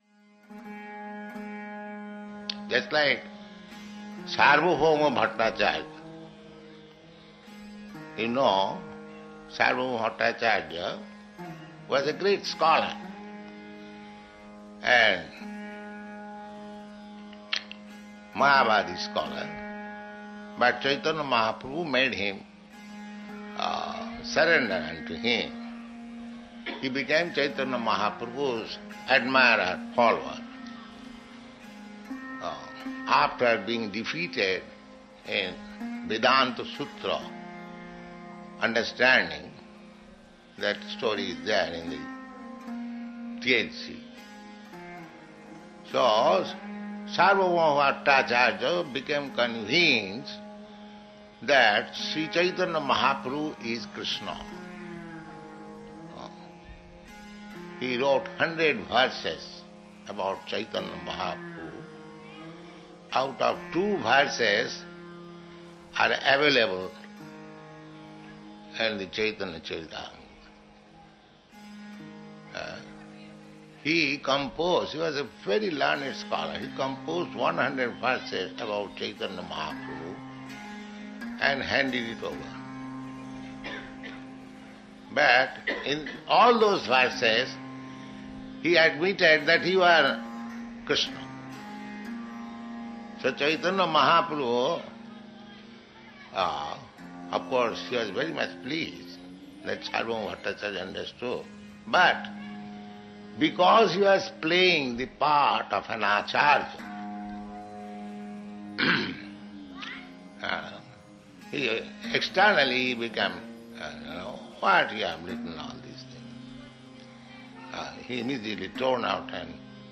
(710731 - Lecture BS 5.35 - New York)